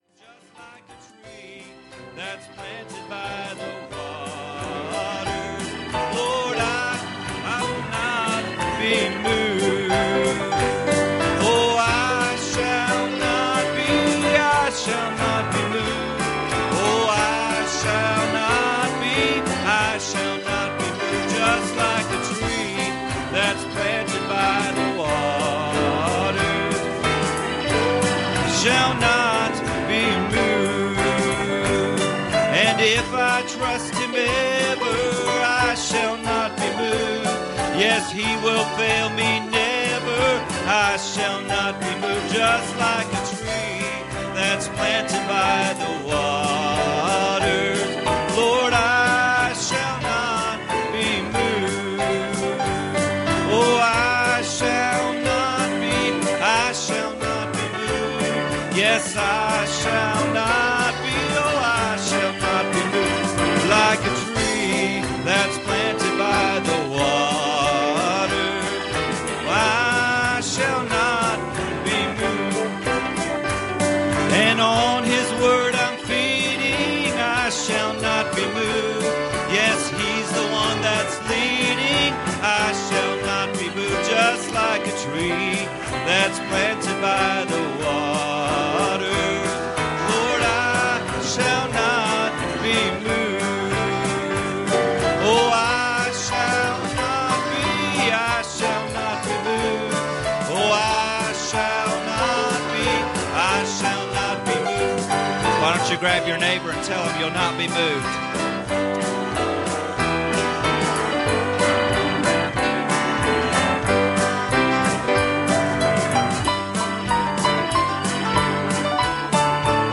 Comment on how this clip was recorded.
Passage: John 1:45 Service Type: Sunday Morning